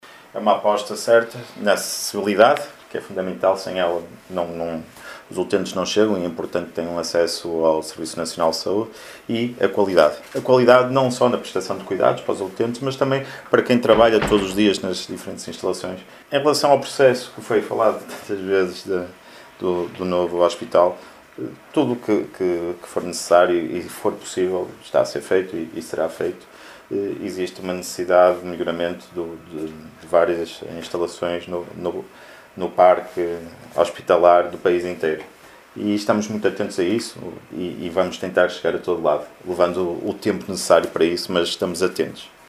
Na resposta, António Gandra D’Almeida, diretor executivo do Serviço Nacional de Saúde, não se comprometeu com datas, mas evidenciou a aposta na melhoria das condições do serviço nacional de saúde: